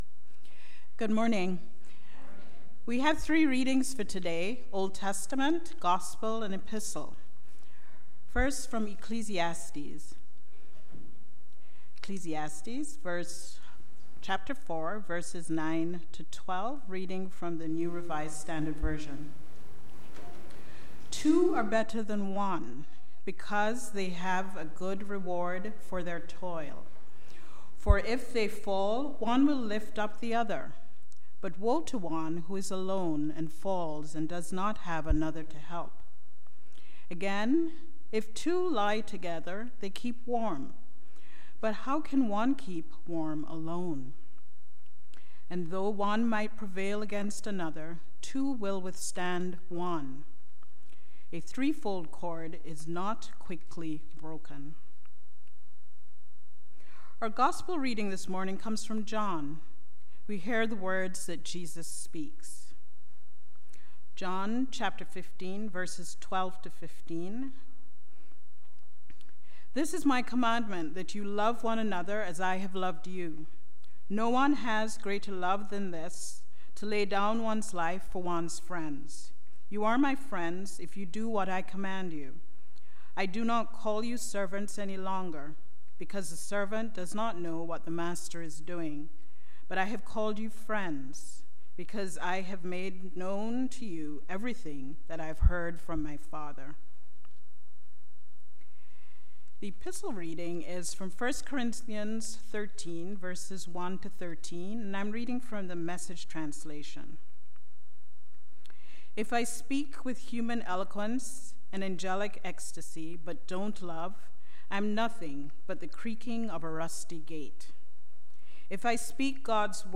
Sermons | Northwood United Church